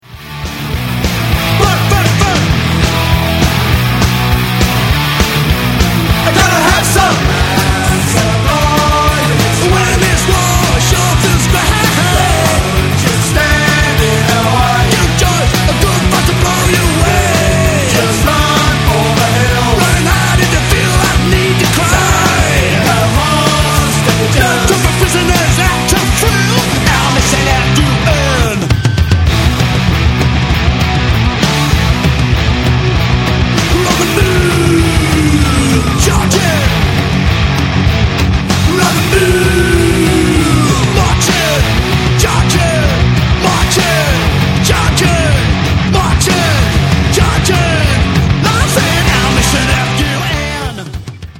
And Local punk-edged rockers